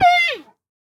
Minecraft Version Minecraft Version snapshot Latest Release | Latest Snapshot snapshot / assets / minecraft / sounds / mob / panda / hurt2.ogg Compare With Compare With Latest Release | Latest Snapshot